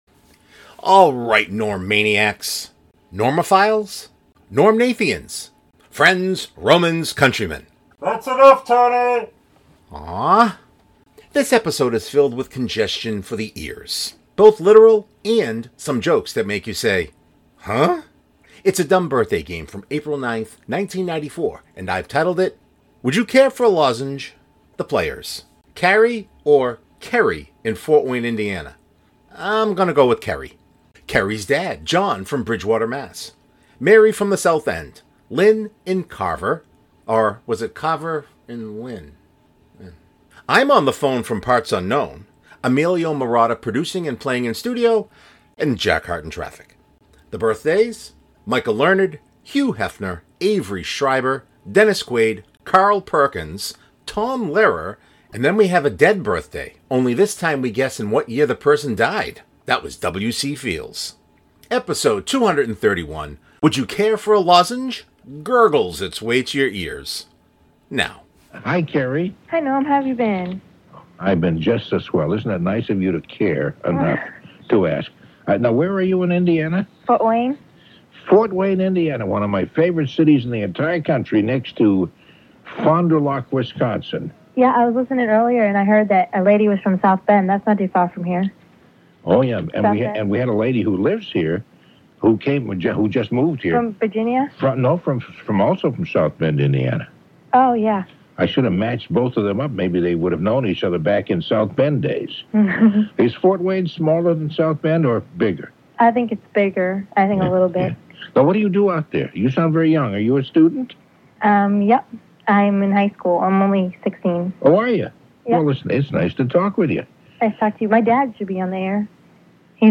This episode is filled with congestion for the ears.